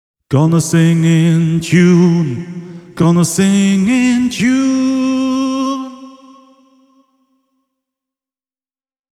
Jokaiseen kaikuun voi lisätä myös viive-efektin:
Tässä yksi pätkä, jossa Correct-säädin on kello kymmenen kohdalla: